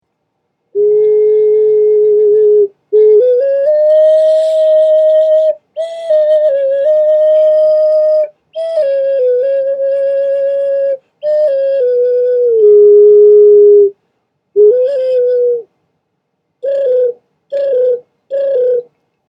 Primitive Frog Ocarina Flute
This Instrument produces a lovely melody.
A recording of the sound of this particular ocarina is in the top description, just click on the play icon to hear the sound.
This musical instrument  is 8 inches x 5 inches across and 5 inches tall. It weighs almost 1 pounds, a cord can be added to make into necklace, has four key holes